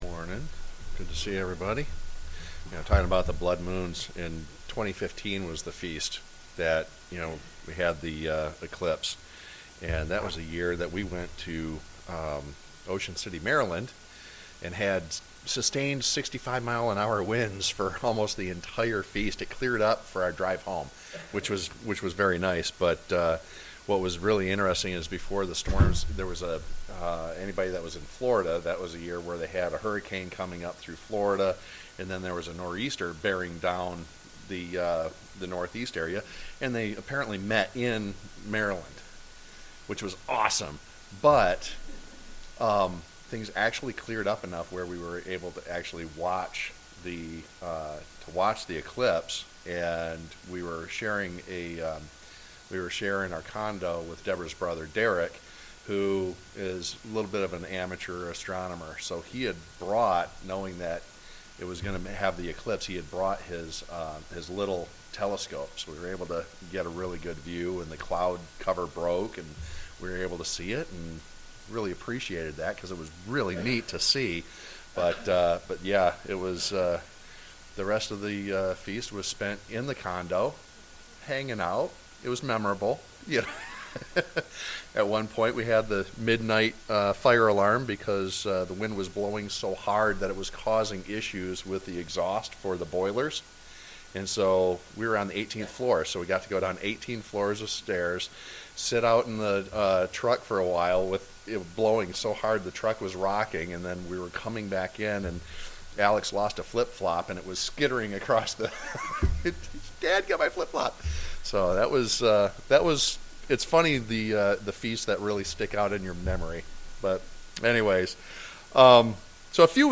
As is our tradition we try to have the Blessing of Children on the second Sabbath after the Feast of Tabernacles, in imitation of Christ lifting up the children into His arms and blessing them, as seen in the Gospels. This blessing is not only for the the children but also for the families, and is an example of God's blessing on His Royal Family, His Children that we may grow up int all things unto Him.